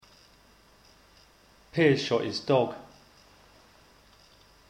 Since heavily stressed syllables are higher in pitch than unstressed syllables, this gives the sentence a melody whereby the pitch falls from Piers to shot, and again to his, and then rises with dog.